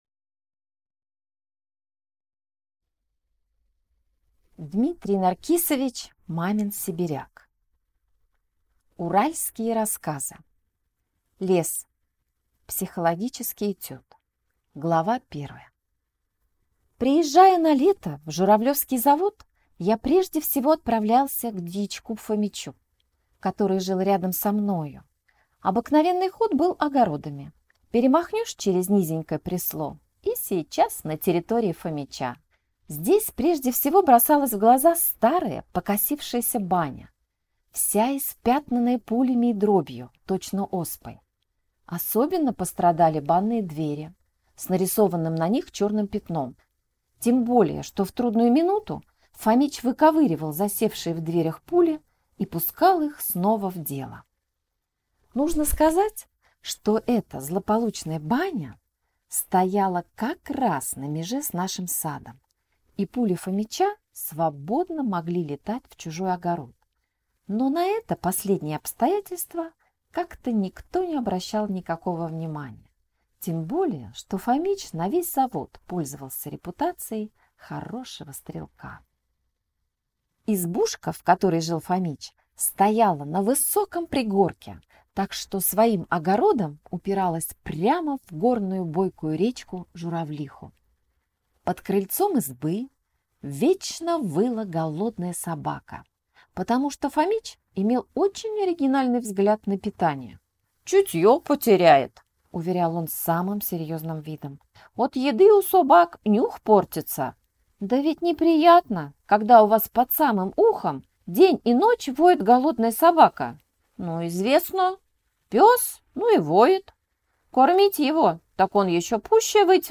Аудиокнига Лес. Психологический этюд | Библиотека аудиокниг